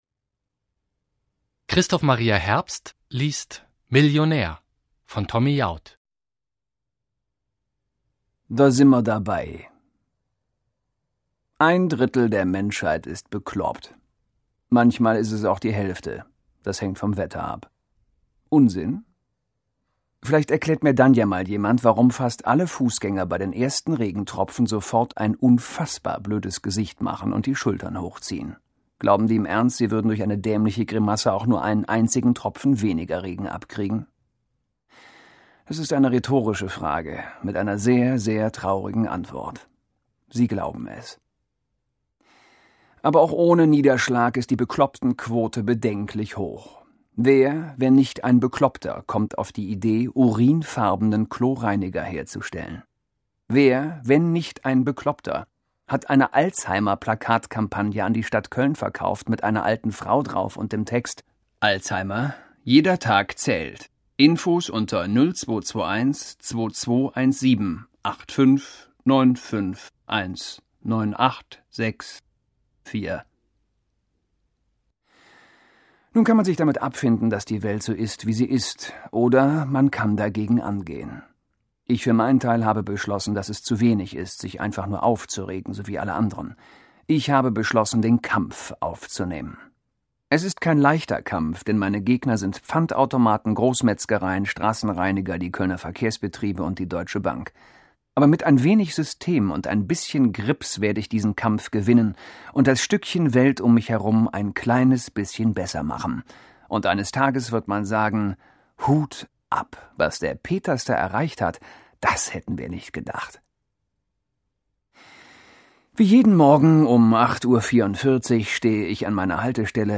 Millionär (DAISY Edition) Tommy Jaud (Autor) Christoph Maria Herbst (Sprecher) Audio-CD 2008 | 1.